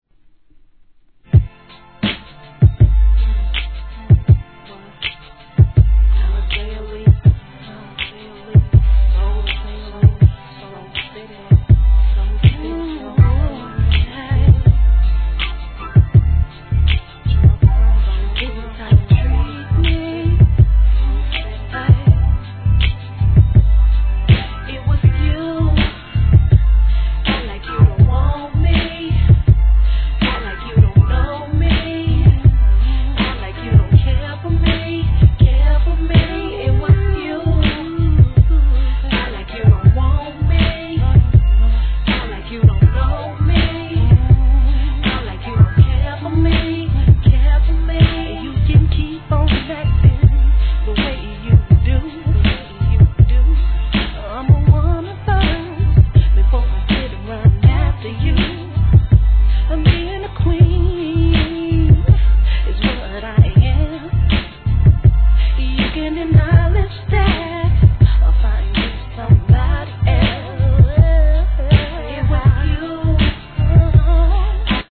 HIP HOP/R&B
ベースを効かせたLOWなBEATでしっとりと歌い上げた激インディーR&B!